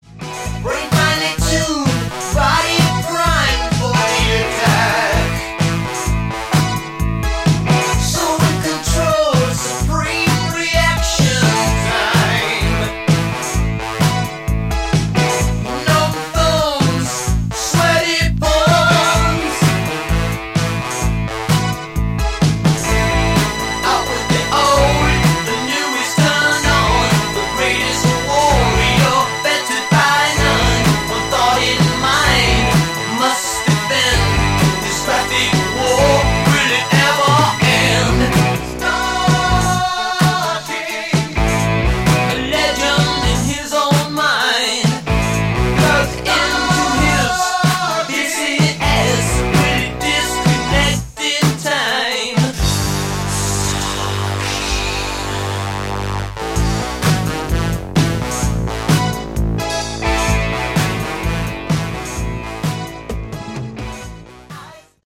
Category: Hard Rock
drums, percussion
lead guitar
bass, backing vocals
lead vocals, rthythm guitar
keyboards, backing vocals